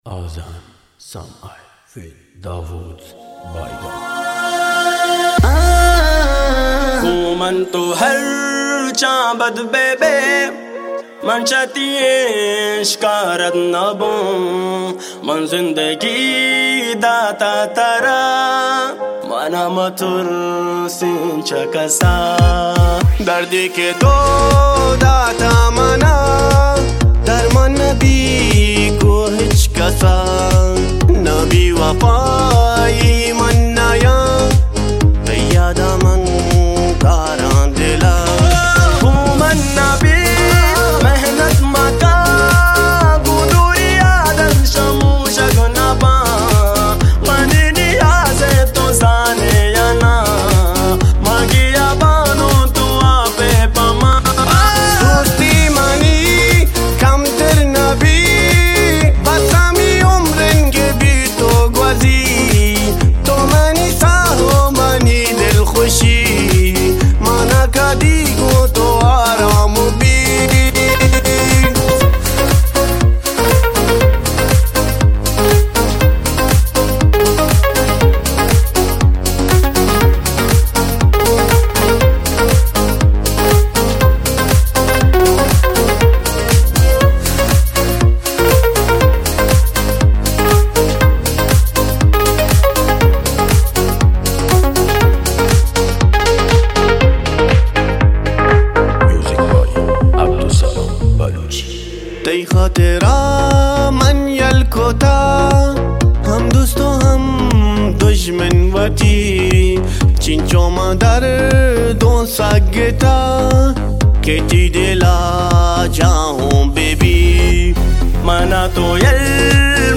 ترانه بلوچی